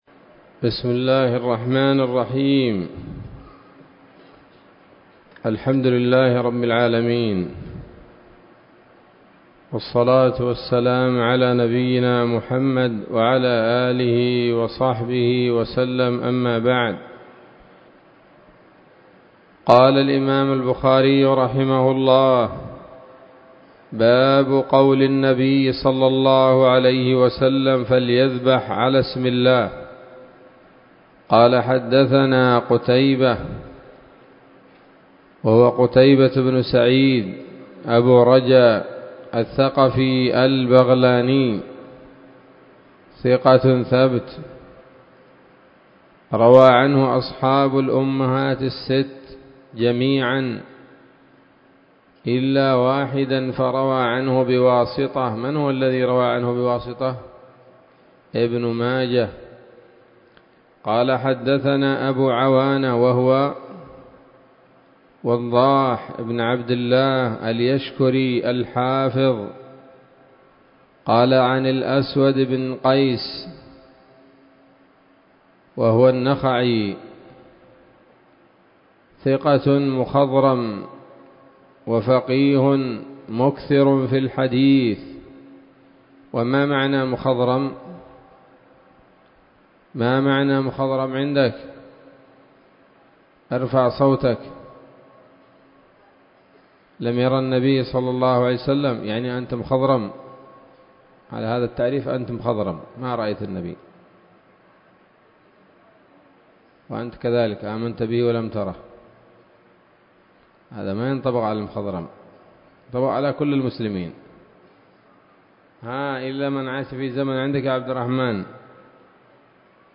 الدرس الخامس عشر من كتاب الذبائح والصيد من صحيح الإمام البخاري